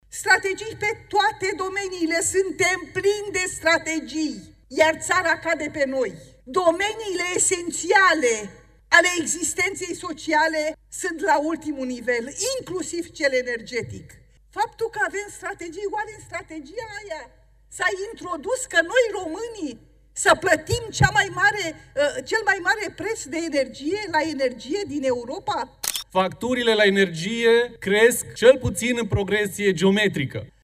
La Parlament, facturile la curent au fost, din nou, în centrul atenției.
Verginia Vedinaș, deputat SOS: „Domeniile esențiale ale existenței sociale sunt la ultimul nivel”
Alin Coleșa, deputat AUR: „Facturile la energie cresc”